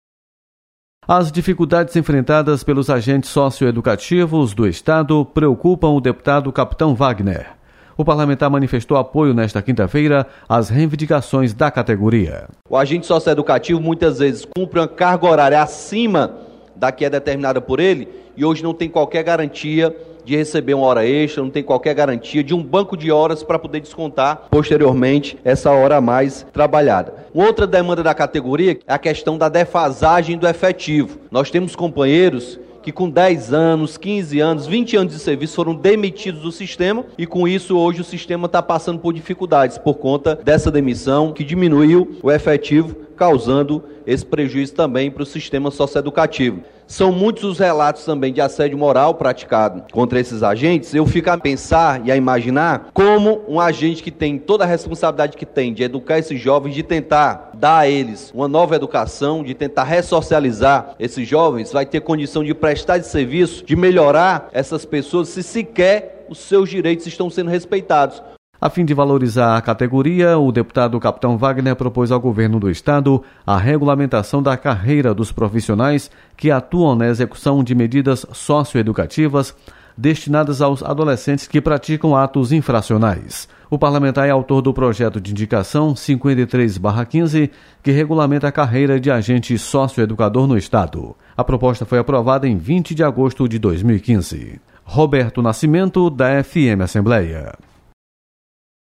Deputado Capitão Wagner manifesta apoio às reivindicações dos agentes socieducativos. Repórter